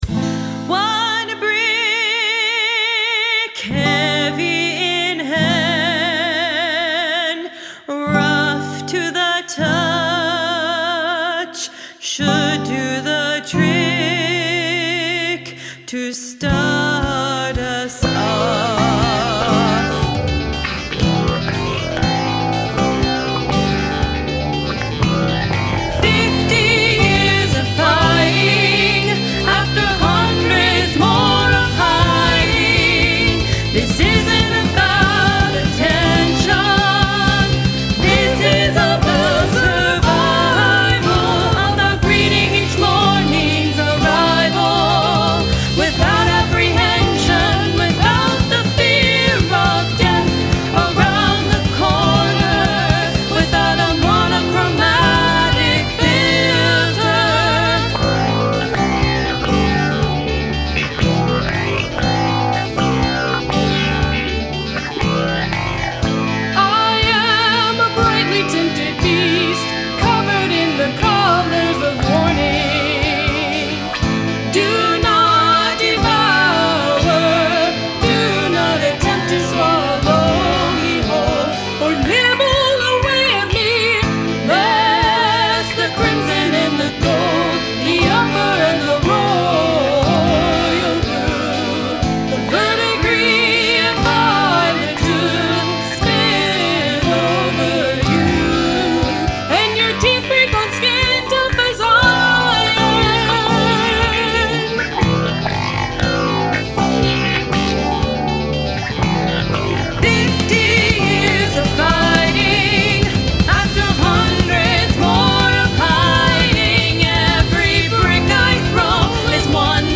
Cool flange-y bass tones.